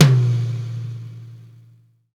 TOM XTOMM0JL.wav